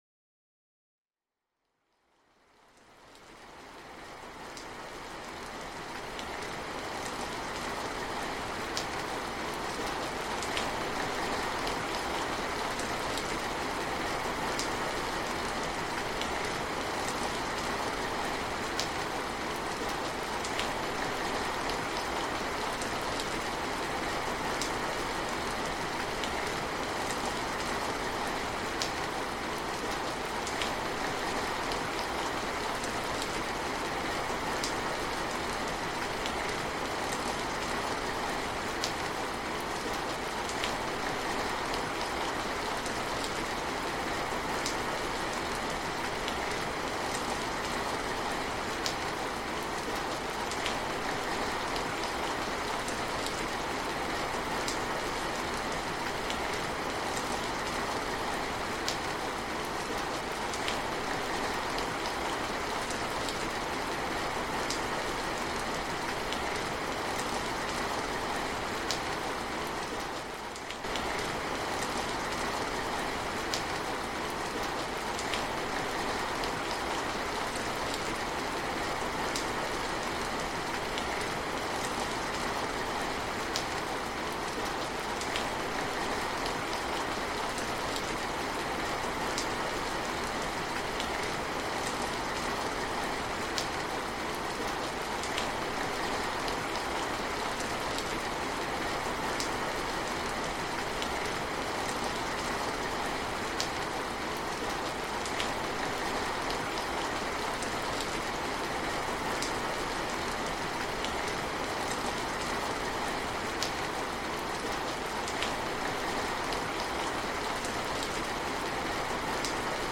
Ambient Stream and Forest Rainfall to Calm the Nervous System for Gentle Concentration – Evening Wind Mix
Each episode of Send Me to Sleep features soothing soundscapes and calming melodies, expertly crafted to melt away the day's tension and invite a peaceful night's rest. Imagine the gentle hum of a distant thunderstorm, the serene flow of a mountain stream, or the soft rustle of leaves in a midnight breeze—sounds that naturally lull you into deep relaxation.